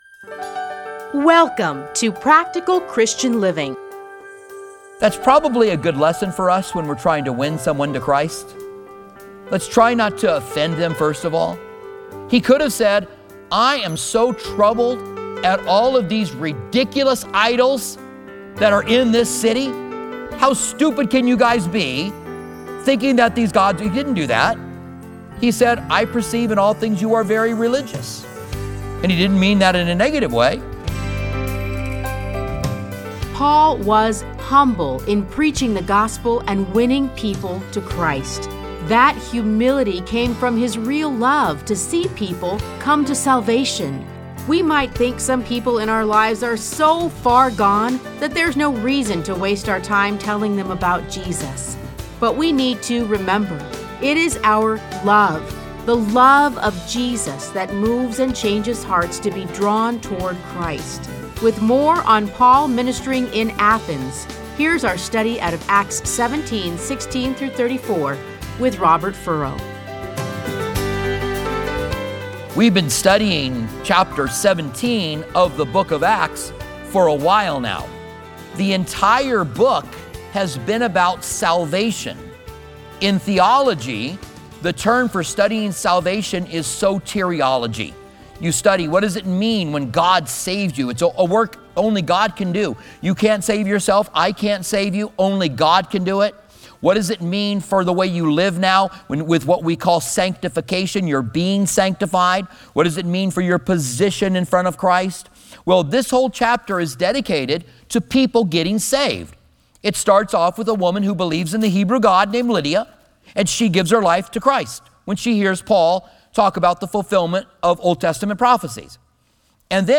The Mars Hill Sermon - Part 1